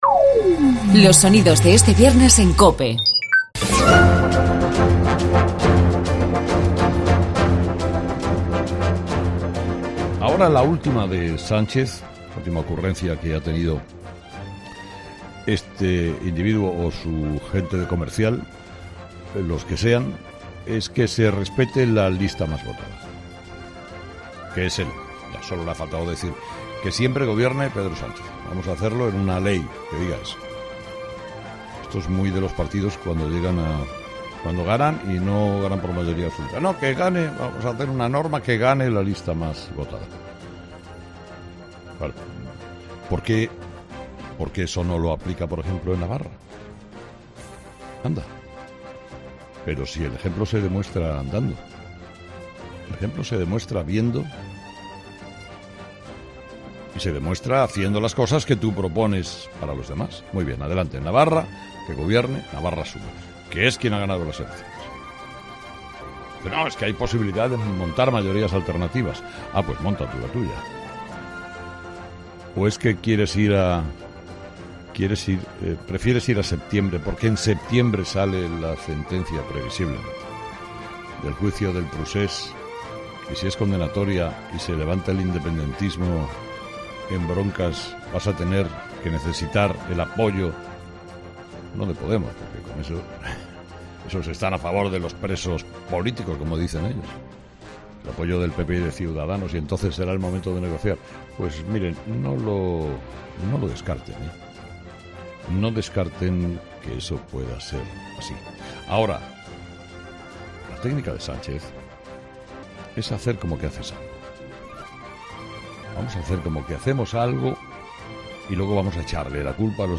En su editorial de este viernes, Herrera ha criticado la propuesta de modificación constitucional planteada por Pedro Sánchez para que sea investido presidente el candidato del partido más votado.
Además, ha sido entrevistado Alonso Alonso, que ha dicho que "a muchos, como al PSOE, les interesa blanquear a la izquierda abertzale”. El presidente del PP vasco ha asegurado que a los socialistas le interesa que Bildu sea una fuerza política con la que se pueda pactar.